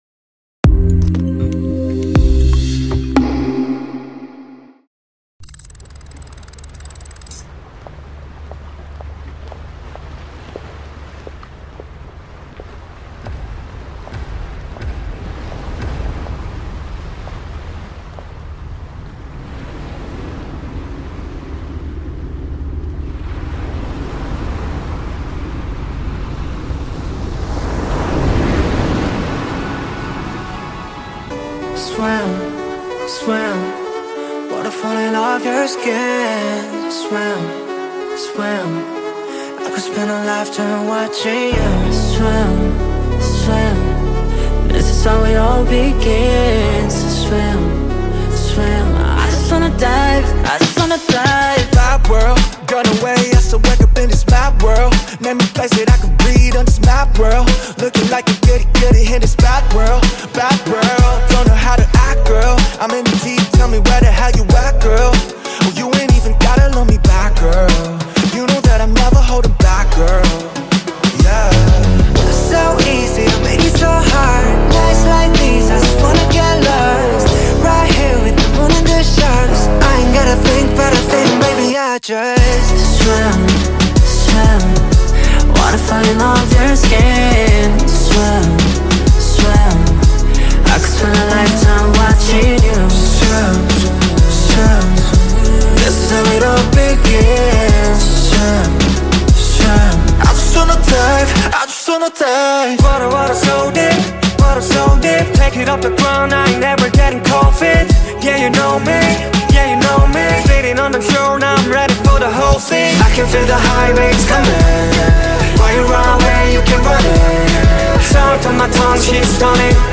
reflective and atmospheric pop track